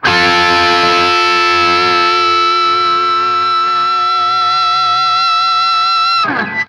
TRIAD F   -R.wav